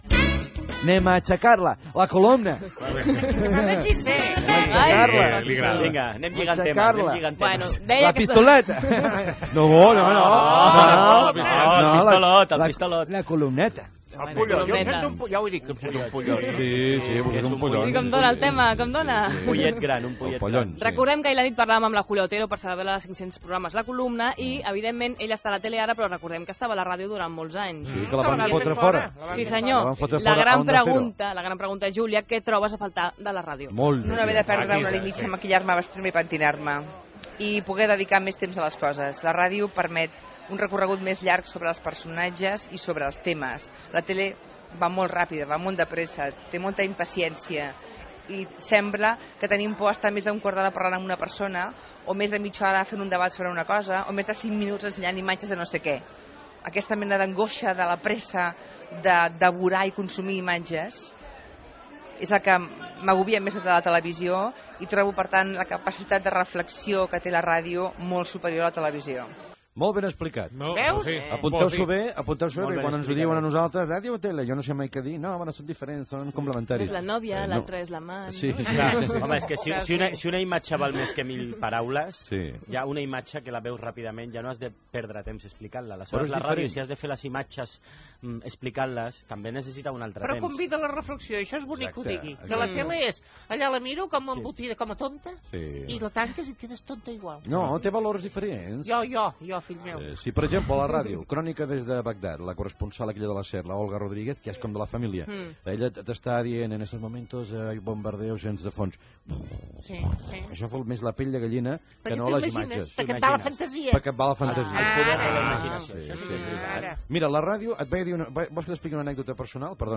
Entreteniment